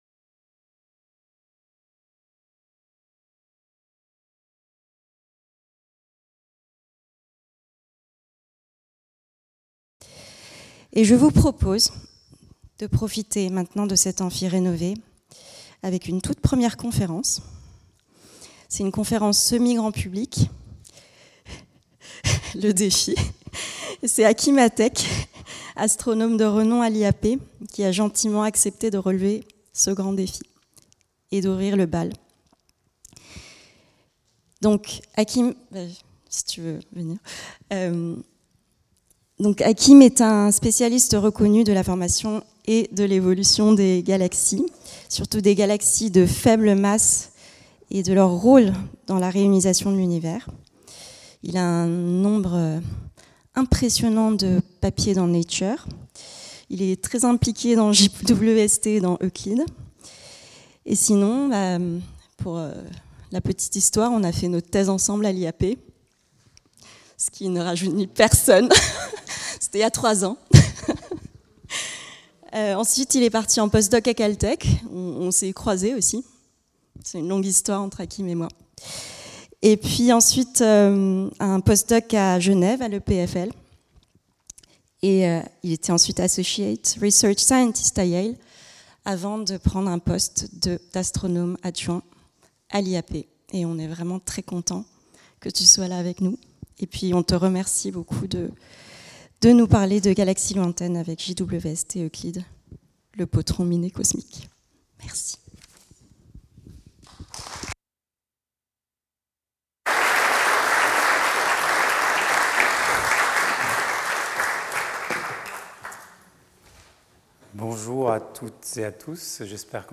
Cette conférence a été enregistrée à l'occasion de l'inauguration de la rénovation de l'amphithéâtre de l'IAP, lundi 15 septembre 2025.